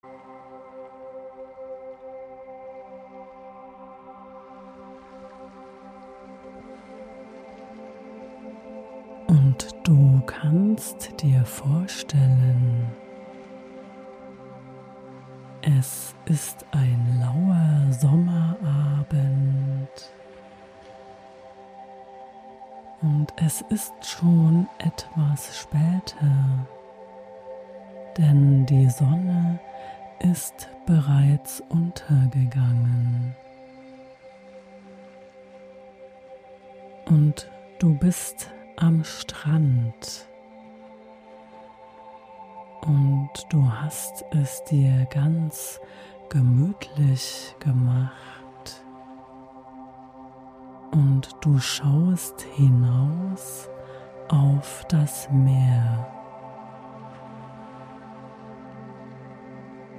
Diese geführte Fantasiereise entführt dich an einen friedlichen Sommerabend am Meer.